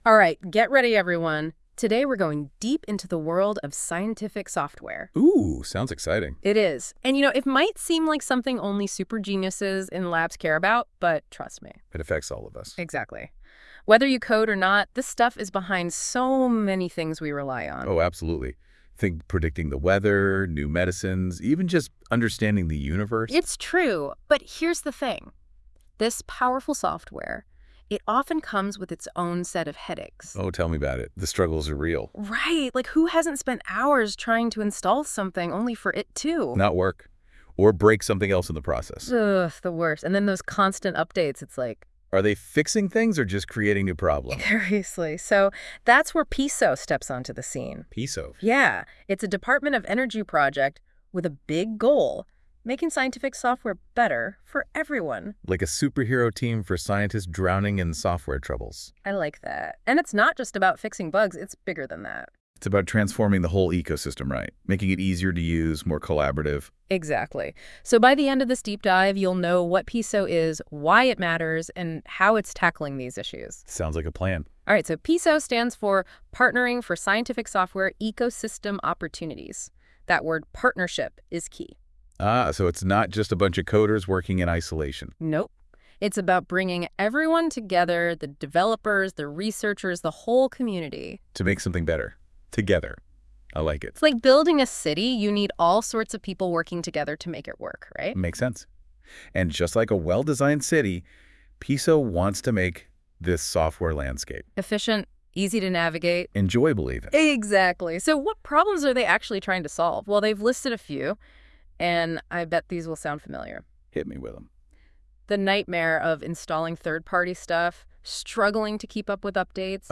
Google NotebookLM Explains the PESO Project!
This podcast provides a fun and useful description of the PESO Project and the products that are a key focus of PESO: E4S and Spack. Pronunciation of PESO is not perfect (pronounced PEE-SO instead of PAY-SO) but the content is still valuable and worth a listen if you want to understand the big picture of the PESO project and all it does.